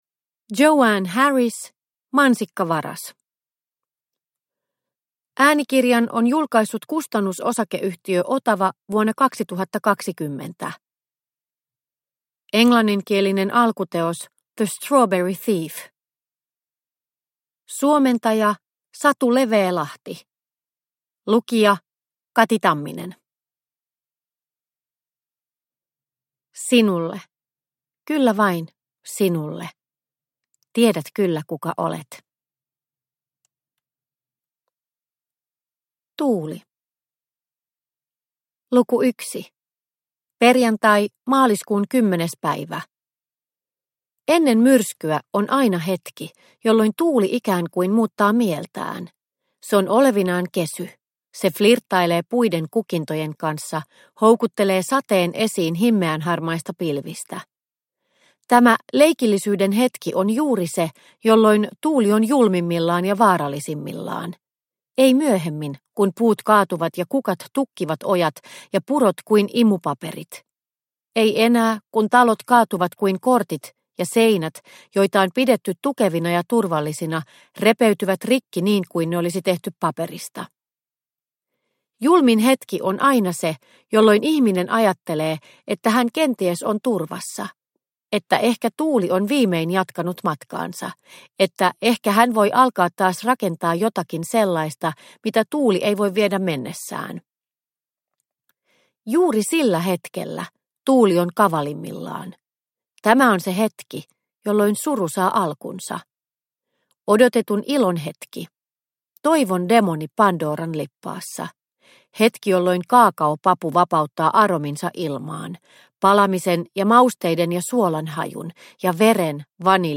Mansikkavaras – Ljudbok – Laddas ner